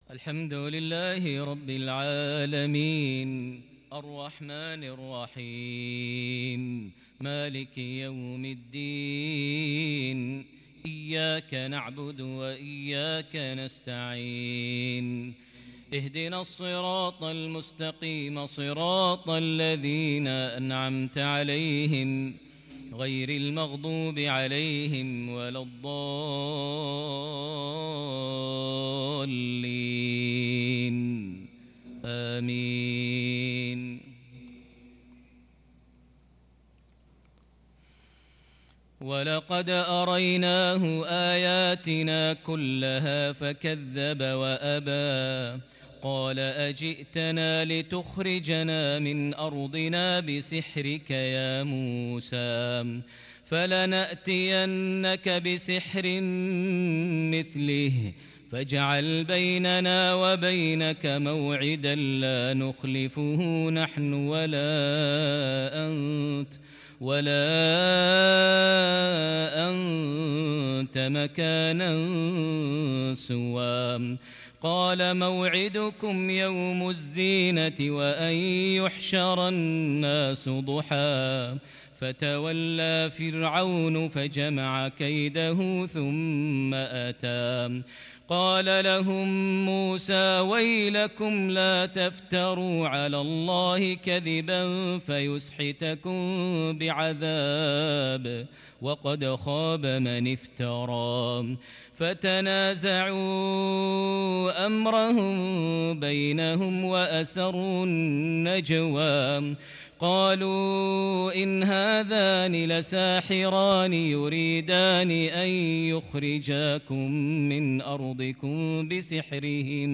صلاة العشاء من سورة طه 19 رجب 1442هـ |lsha 3-3-2021 prayer fromSurah Ta-Ha 65-76 > 1442 🕋 > الفروض - تلاوات الحرمين